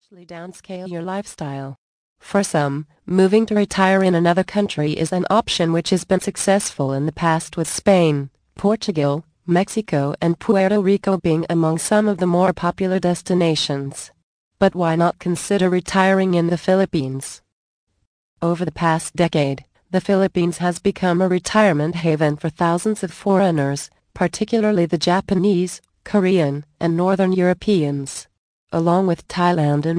Easy Retirement Planning Tips Audio Book. Vol. 2 of 8